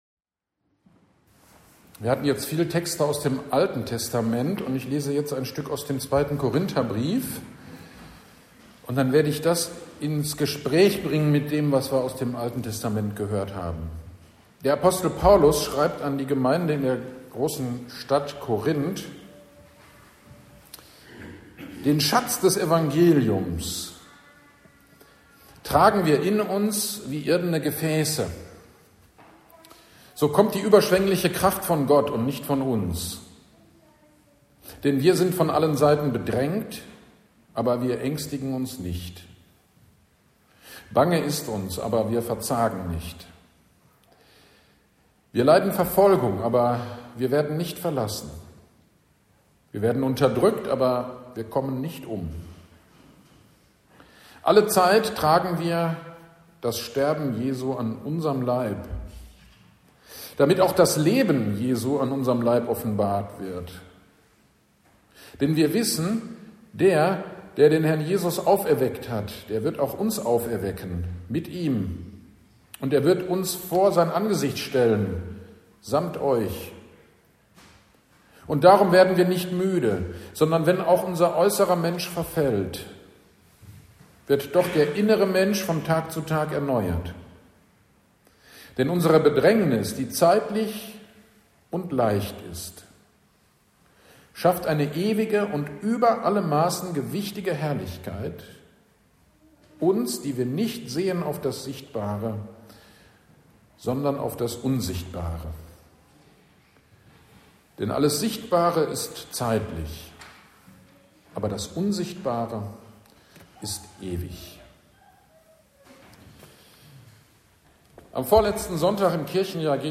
GD am 16.11.2025 Predigt zu 1. Johannes 5, 4 - Kirchgemeinde Pölzig